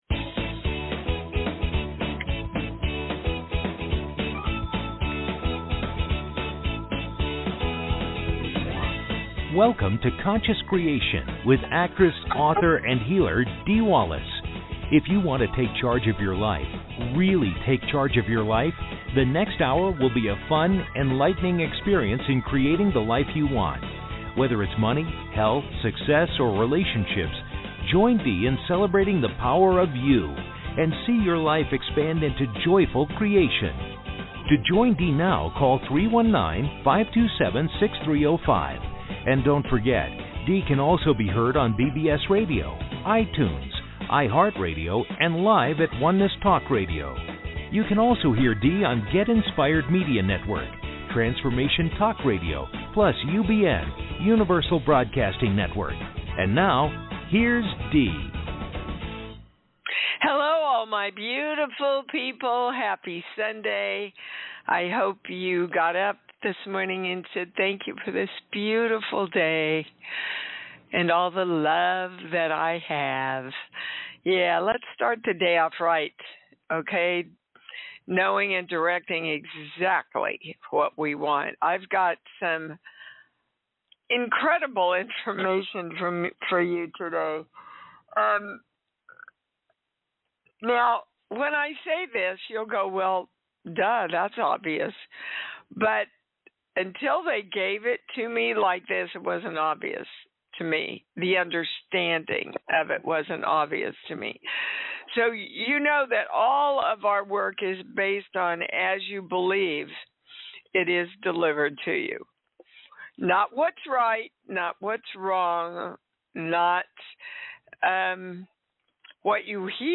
Talk Show Episode, Audio Podcast, Conscious Creation and with Dee Wallace on , show guests , about Dee Wallace,conscious creation,I am Dee Wallace, categorized as Kids & Family,Philosophy,Psychology,Self Help,Society and Culture,Spiritual,Access Consciousness,Medium & Channeling,Psychic & Intuitive